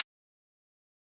Index of /musicradar/ultimate-hihat-samples/Hits/ElectroHat C
UHH_ElectroHatC_Hit-01.wav